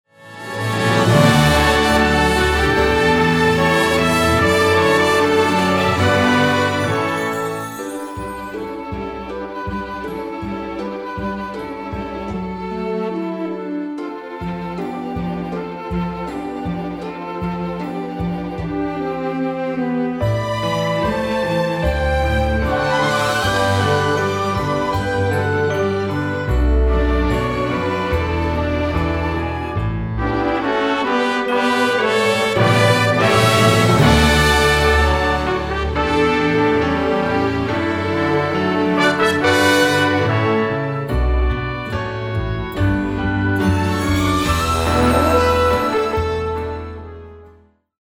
performance track
Instrumental
orchestral , backing track